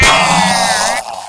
sentry_damage1.wav